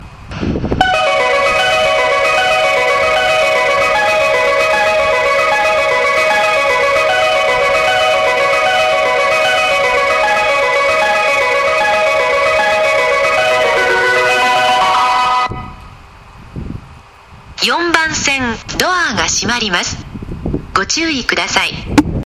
スピーカーは１〜４番線ともに小ボスが使用されており音質はとてもいいと思います。
発車メロディートレモロ切りです。